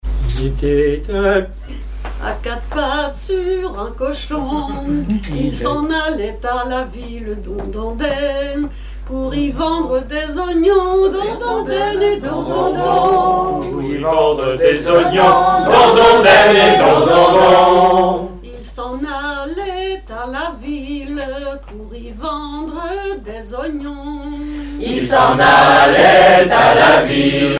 Mémoires et Patrimoines vivants - RaddO est une base de données d'archives iconographiques et sonores.
Chansons et commentaires
Pièce musicale inédite